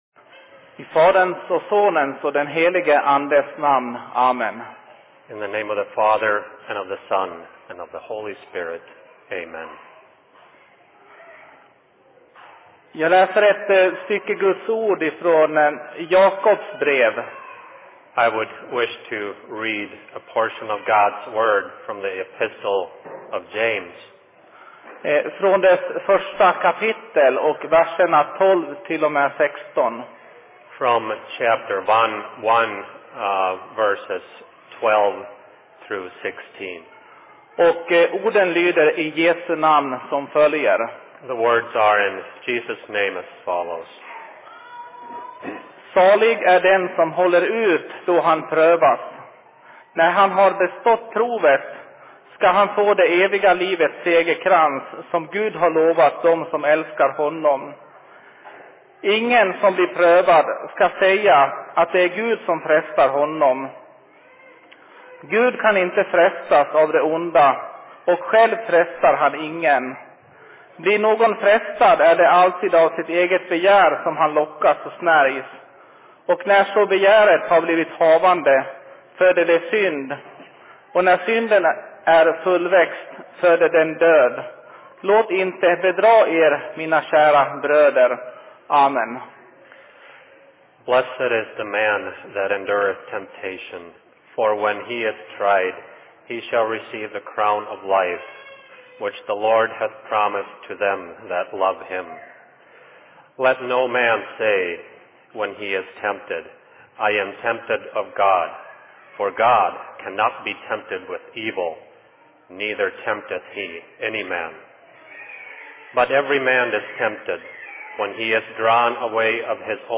Se En Sermon in Rockford 15.02.2012
Plats: LLC Rockford
2012 Simultantolkat Svenska, Engelska Bok: Jakob Skriftställe: James 1:12-16 Tagg